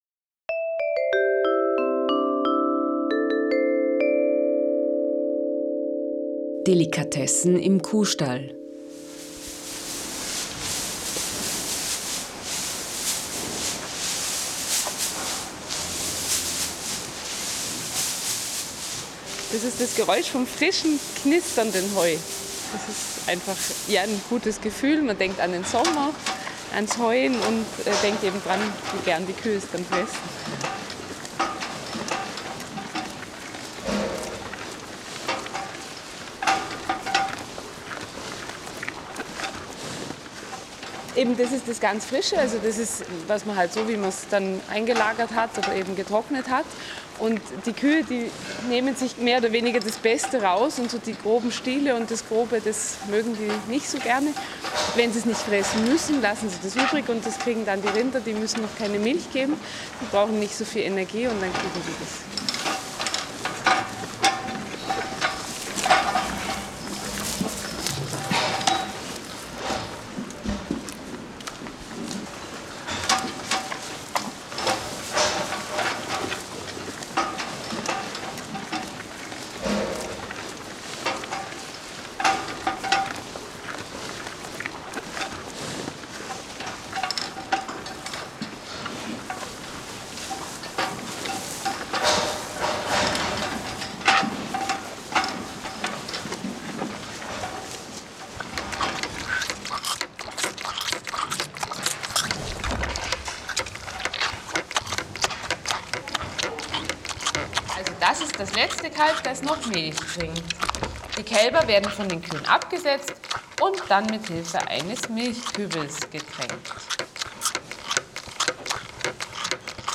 Ihre Erzählungen und die damit assoziierten Geräusche verbinden sich mit den eigens dazu komponierten und eingespielten Musikfragmenten zu neun sehr unterschiedlichen Klangreisen in vergangene und gegenwärtige Welten.
Das Kalb, das am Milchbehälter saugt, hat seine Stimme in der Delikatessen-Partitur.